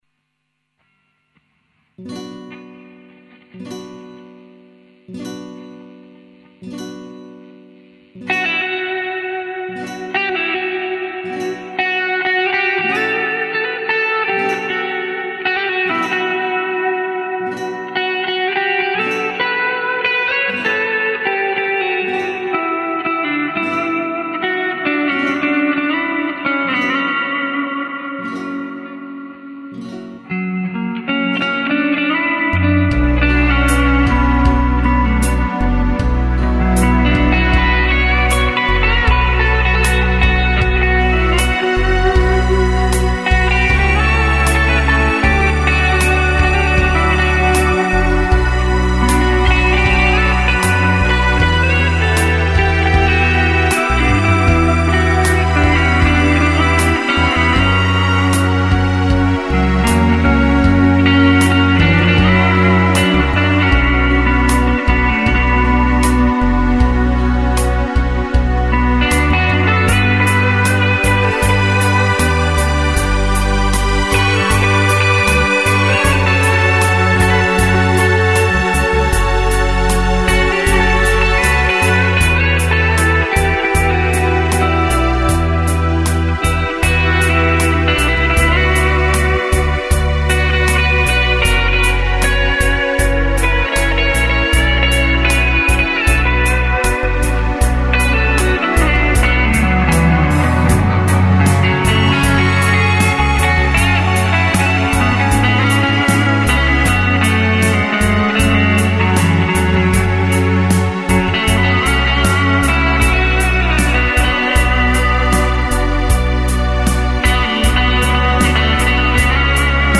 I heard this backing track to the famous Rodrigo adagio, and decided to improvise a slightly bluesy melody over it. The guitar is an MC58, neck pickup.